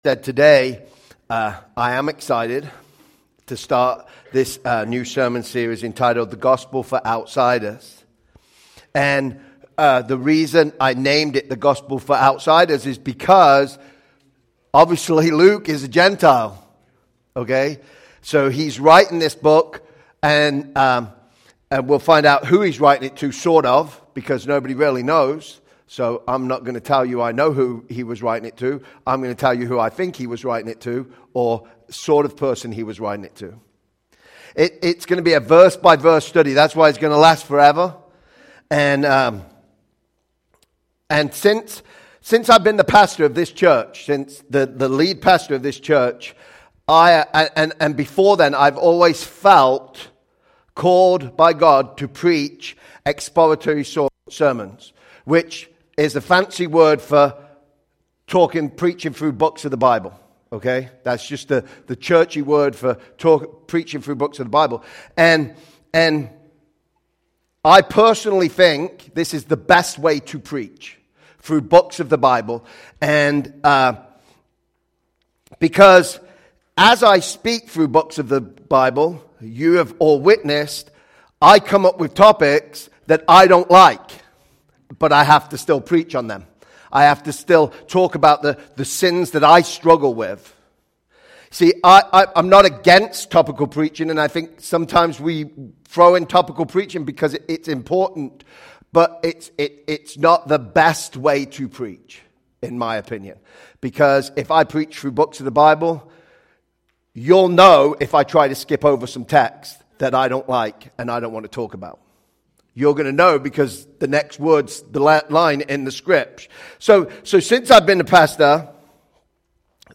Sermons by Calvary Memorial Church Rockford